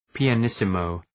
Προφορά
{,pıə’nısı,məʋ} (Επίρρημα) ● πολύ σιγά